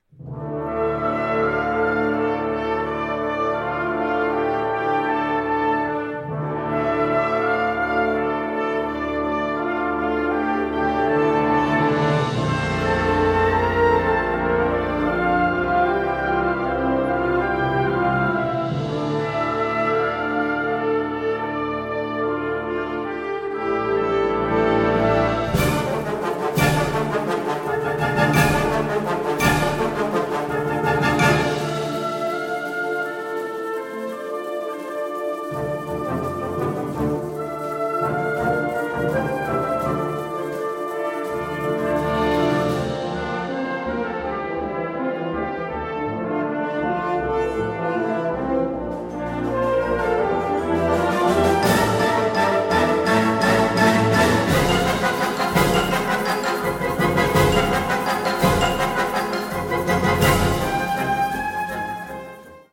Kategorie Blasorchester/HaFaBra
Unterkategorie Konzertpolka
Besetzung Ha (Blasorchester)